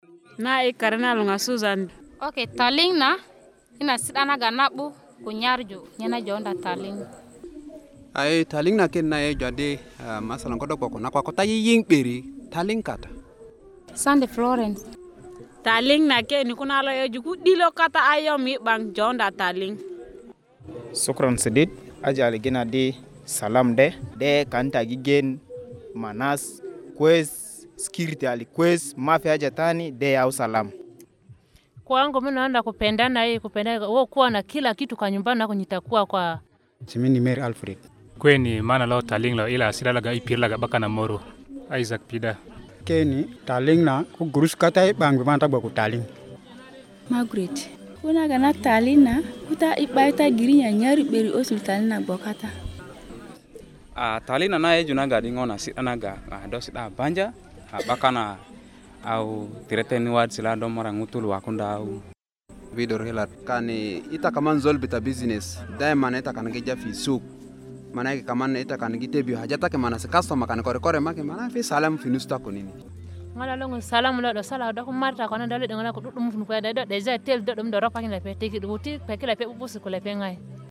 Click play to hear community members voice their responses in Bari and Juba Arabic.